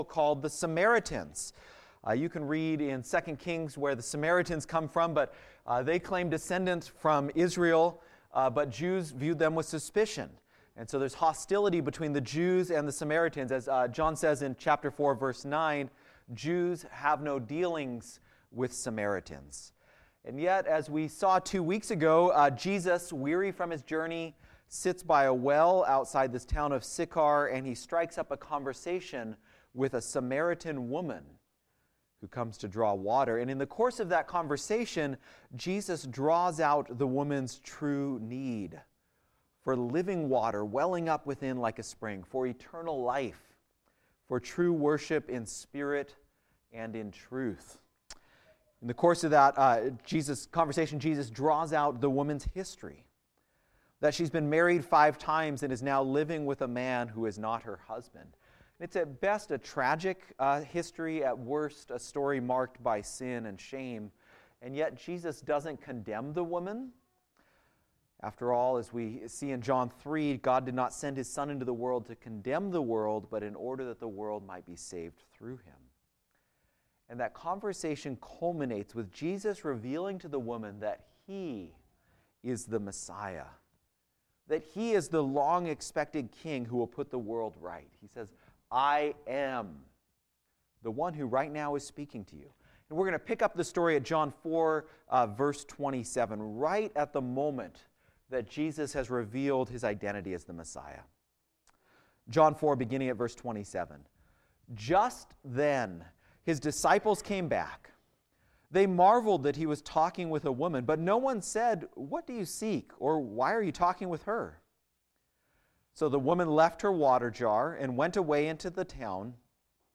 Be a guest on this podcast Language: en Genres: Christianity , Religion & Spirituality Contact email: Get it Feed URL: Get it iTunes ID: Get it Get all podcast data Listen Now... John 4:27-42